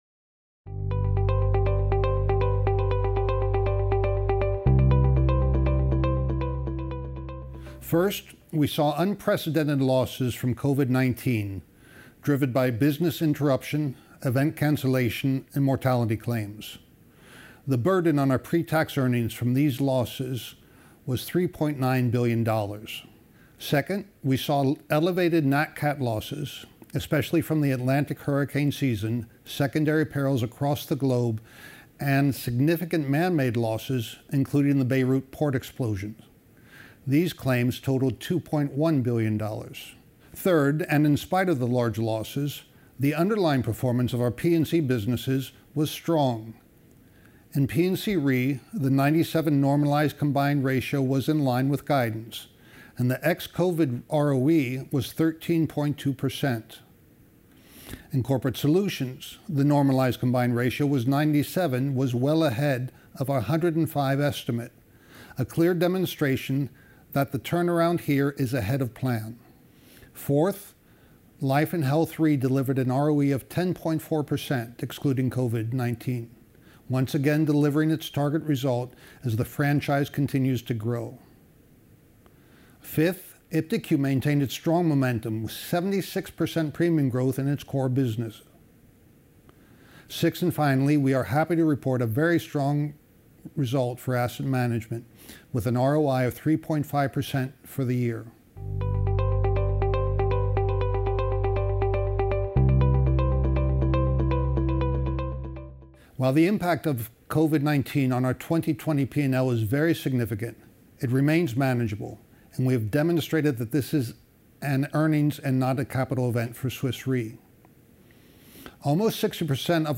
fy-2020-audio-presentation.mp3